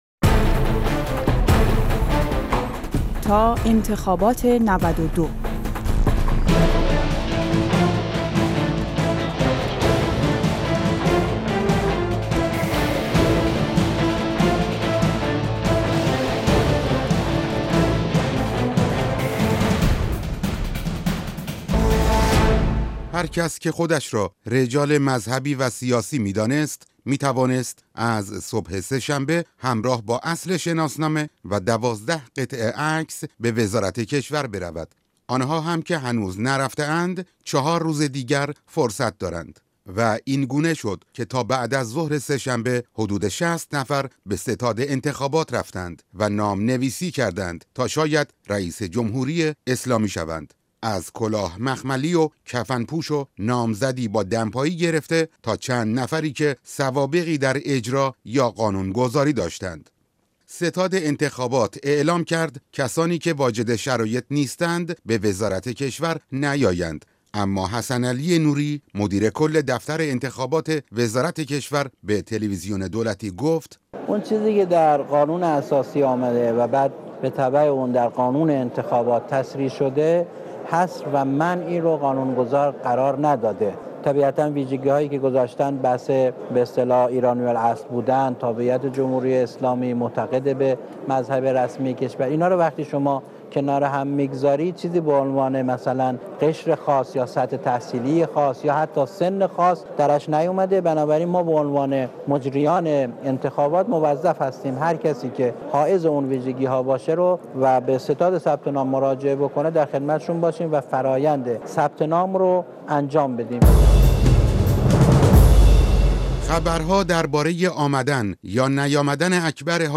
«تا انتخابات ۹۲»، گزارش خبری روزانه رادیو فرداست که خبرهای انتخاباتی را در هر روز پوشش می‌دهد.